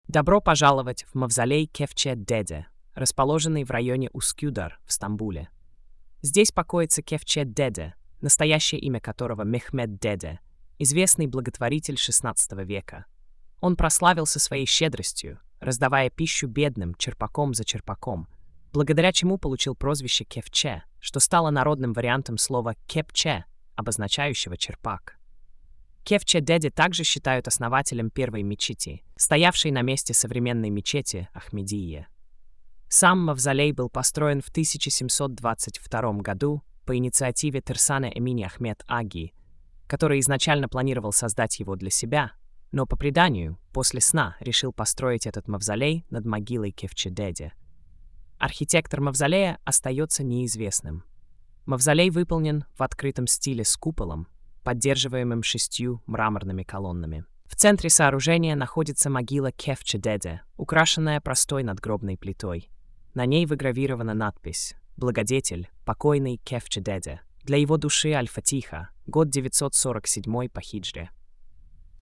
Аудиоповествование: